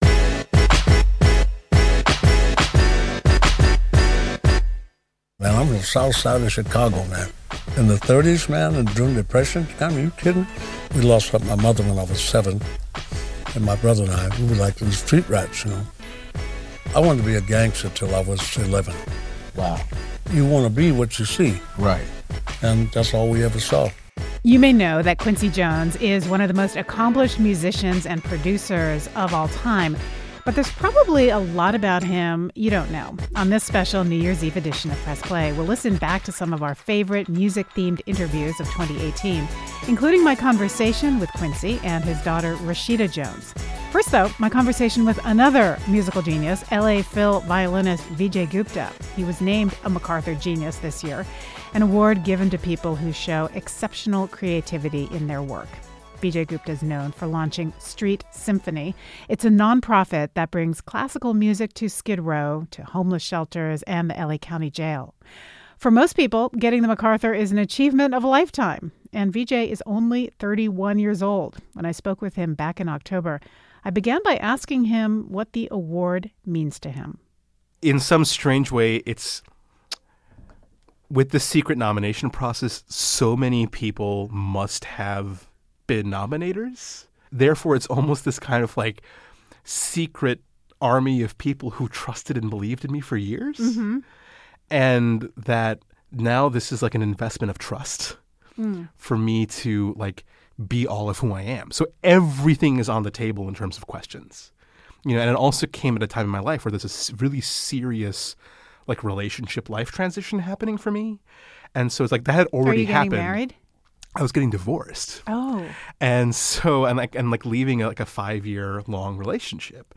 New Year’s Eve: Favorite music interviews from 2018 | KCRW